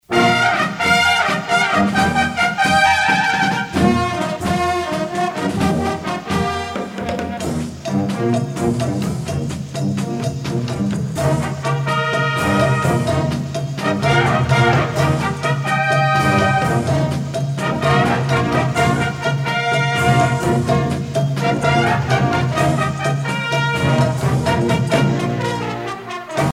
danse : samba
circonstance : militaire
Pièce musicale éditée